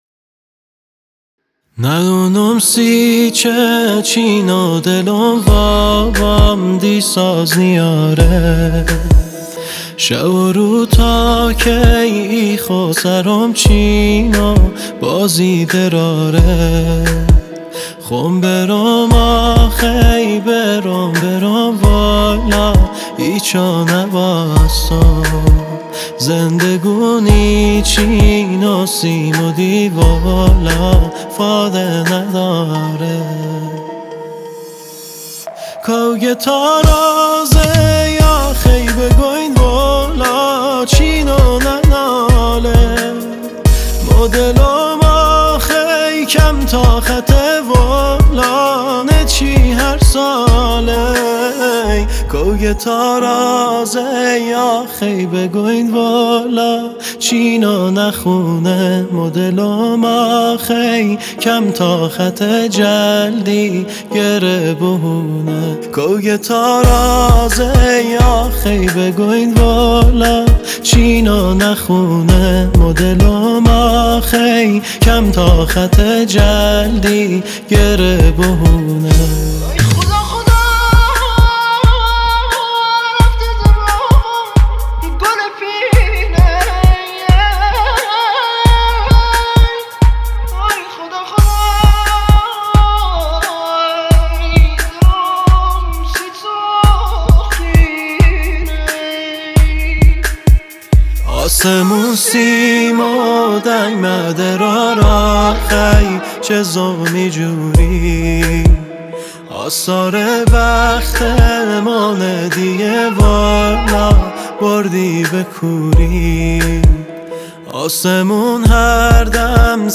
اهنگ بختیاری